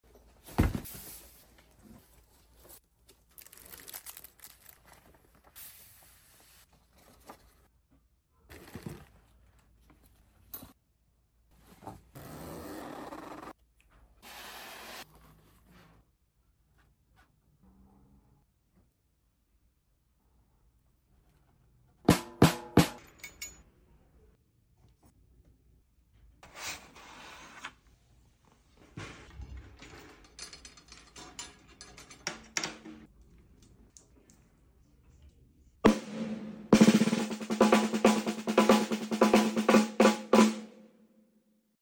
Som de uma Caixa de sound effects free download